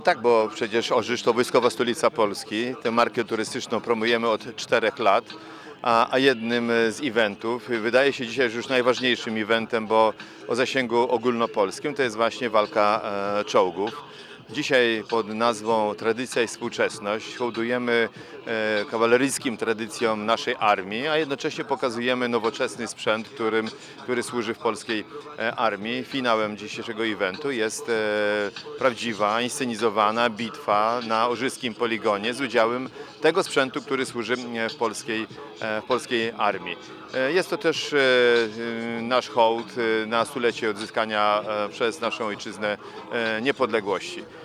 Orzysz określany jest mianem Wojskowej Stolicy Polski i – jak podkreśla Zbigniew Włodkowski, burmistrz Orzysza – Bitwa Czołgów, jest to bardzo ważne wydarzenie dla miasta.